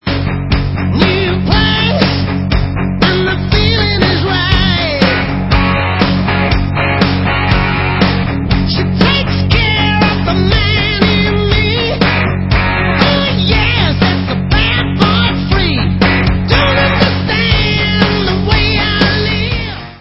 rocková kapela
studiové album